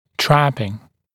[‘træpɪŋ][‘трэпин]блокировка, «попадание в ловушку», когда одна группа зубов блокирует другую группу зубов (обычно о вертикальном соотношении фронтальных сегментов)